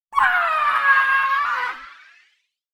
爽やかな音色が特徴の、ボタンクリック音。